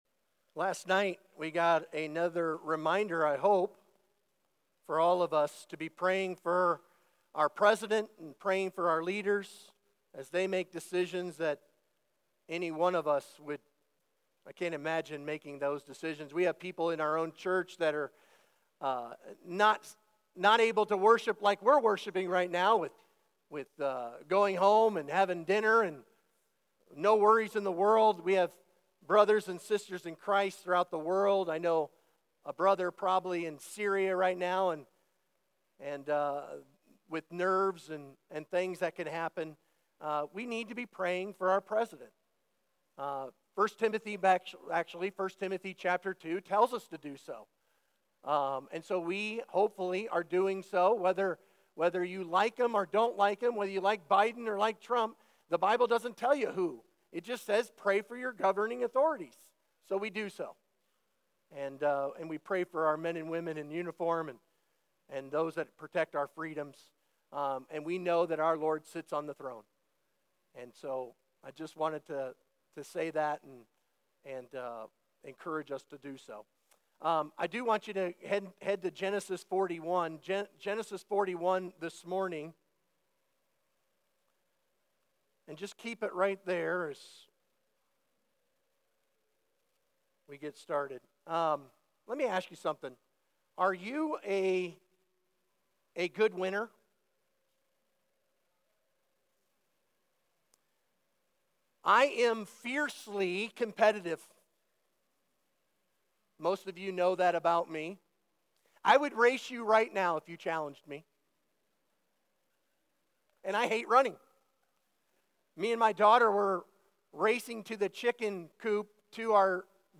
Sermon Questions Read Genesis 41:15–57 aloud.